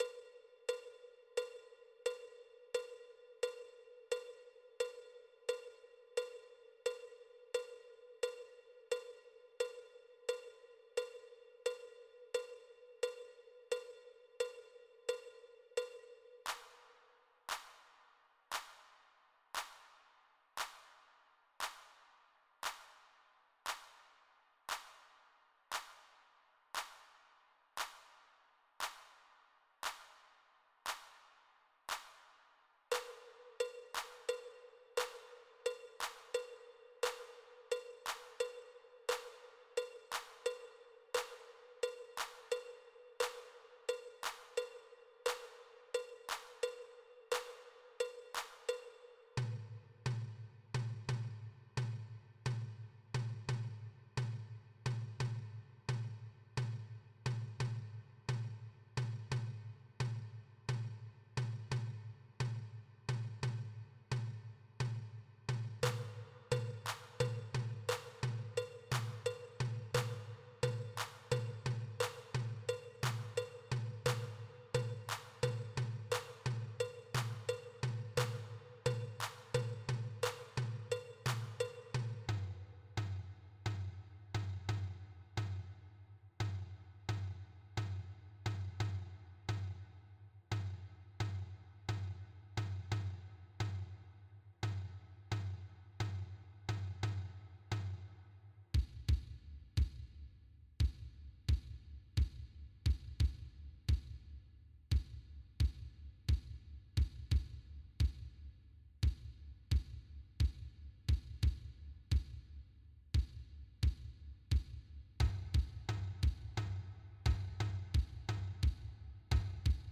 West African Polyrhythms (mp3) - slower
West African Polyrhythms 175.mp3